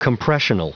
Prononciation du mot compressional en anglais (fichier audio)
Entrez un mot en anglais, et nous le prononcerons pour vous.